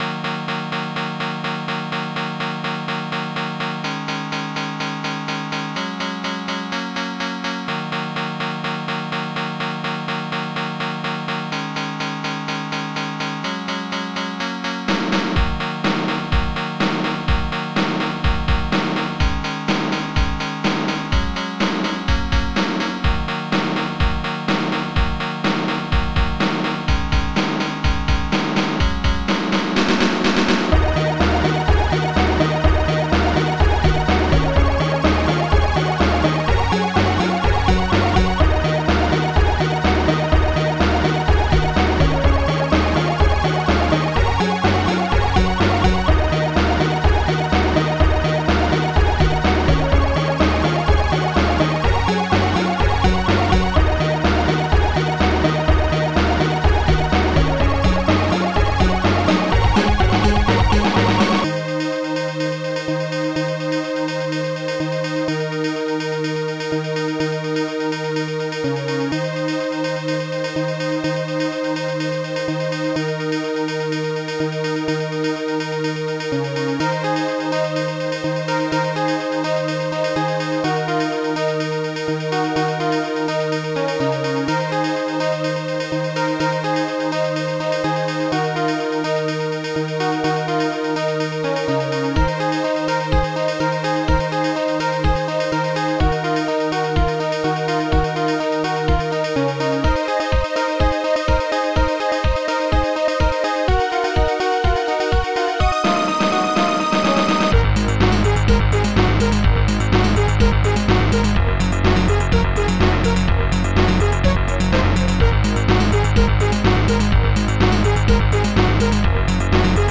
Protracker and family
st-01:steinway
st-07:bassdrum10
st-05:snaredrum4
st-01:strings2
st-01:hallbrass
st-01:hihat1